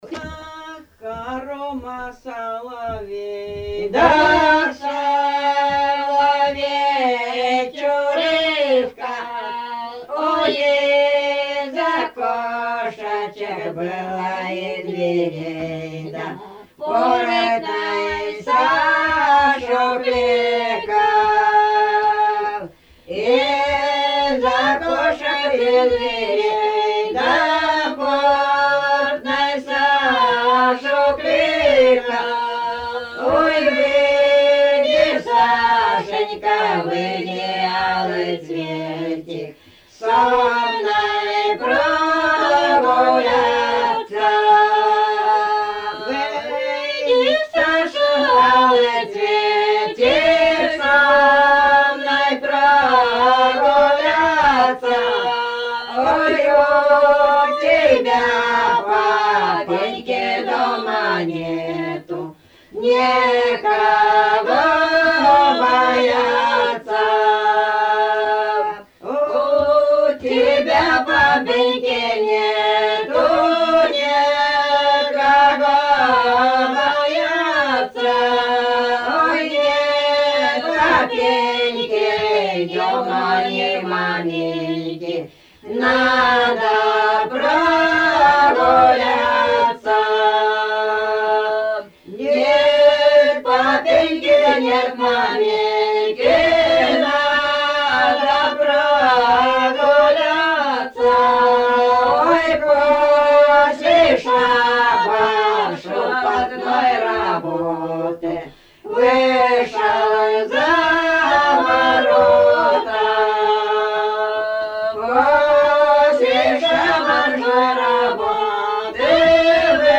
Исполнитель: фольклорная группа с. Шуньга
Место записи: с. Шуньга, Медвежьегорский район, Республика Карелия.